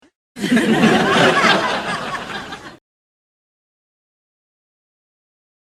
Laughs 6